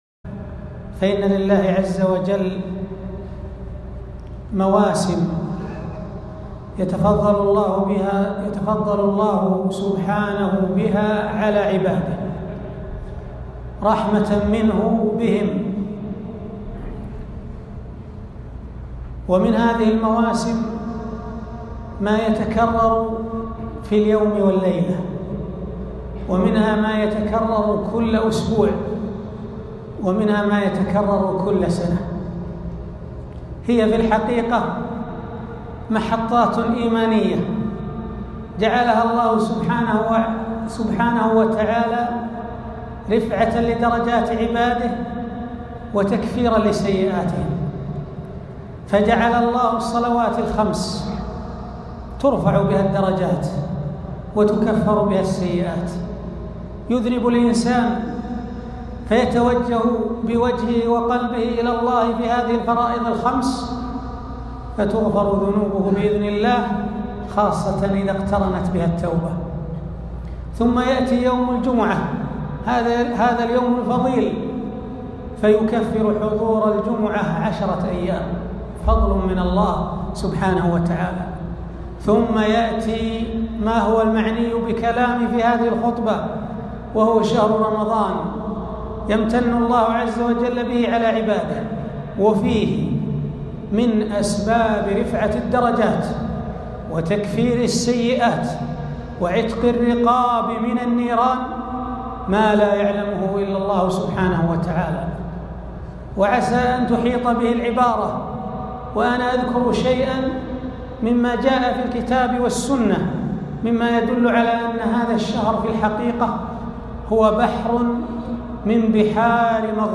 موعظة عن دخول شهر رمضان